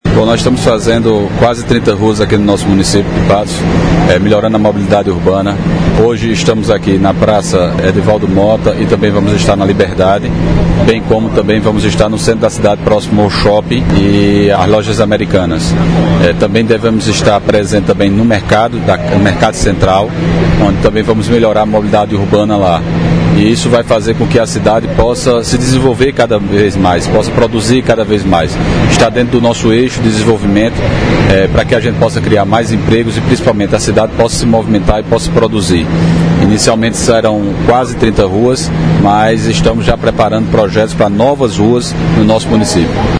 Fala do prefeito Dinaldinho Wanderley – Download Comentário Comentário Compartilhe isso: WhatsApp E-mail Facebook Mais Telegram Curtir isso: Curtir Carregando...